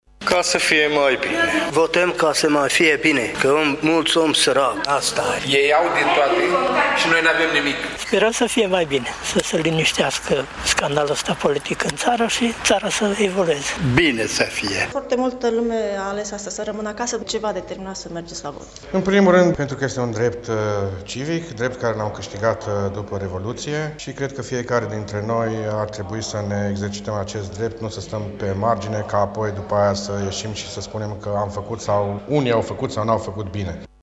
Ei spun că este dreptul lor constituțional să voteze, iar cei care aleg să stea acasă nu sunt cu adevărat implicați în deciziile pentru România: